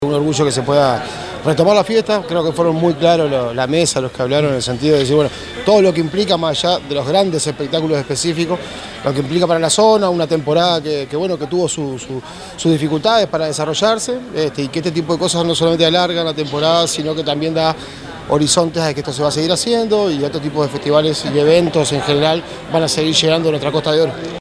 El Director General de Administración, Pedro Irigoin, consideró que es un orgullo poder retomar esta fiesta después de dos años de pandemia.
pedro_irigoin_-_director_general_de_administracion.mp3